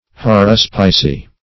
Search Result for " haruspicy" : The Collaborative International Dictionary of English v.0.48: Haruspicy \Ha*rus"pi*cy\ (-p[i^]*s[y^]), n. The art or practices of haruspices.